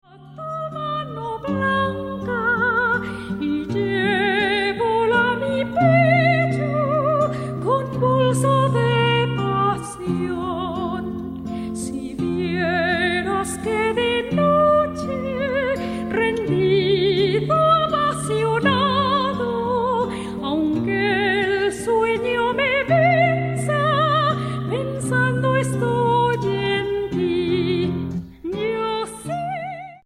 Canciones populares y danzas mexicanas del siglo XIX
guitarra séptima y sexta